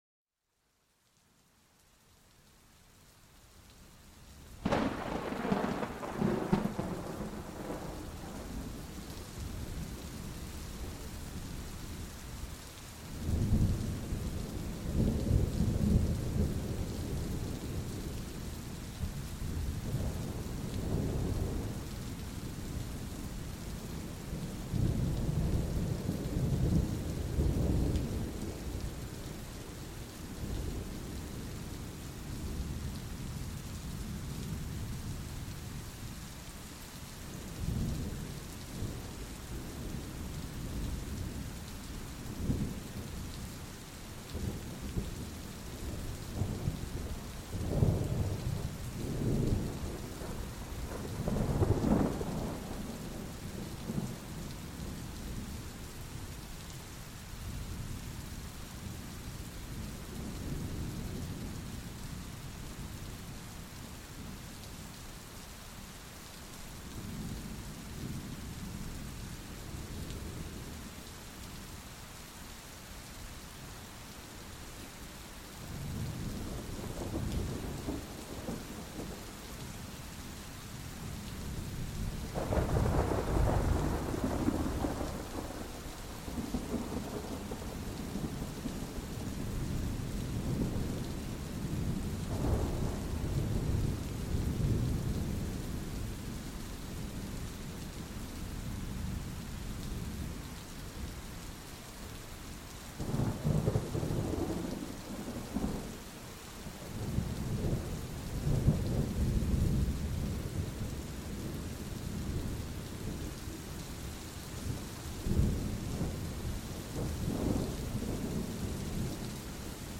⛈ Lluvia Suave y Tormentas: Una Calma Poderosa para la Mente
Explora los sonidos calmantes de la lluvia intensa mezclados con los ecos profundos de las tormentas.
Cada episodio te envuelve en una atmósfera calmante y rejuvenecedora.